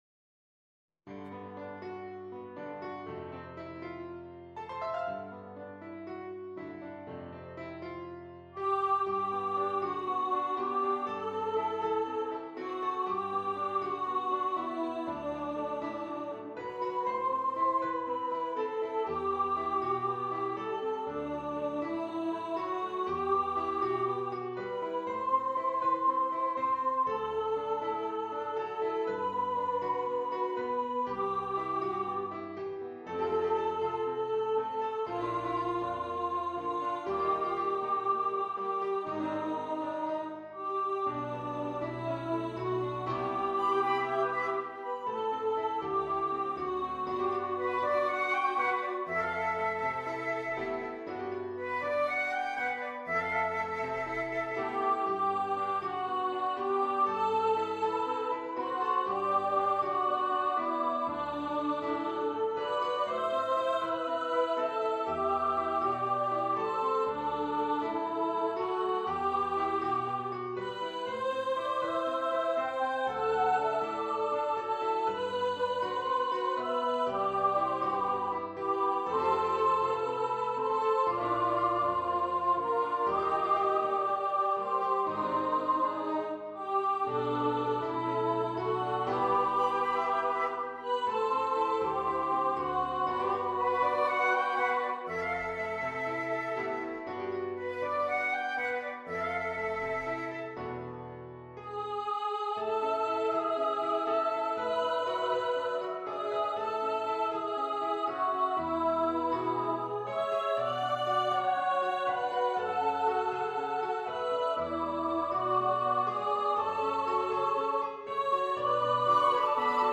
for upper voice choir
in a Caribbean style